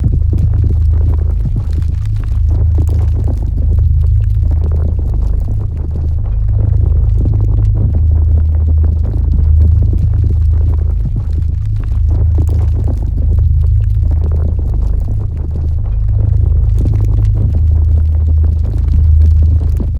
gravitational_idle.ogg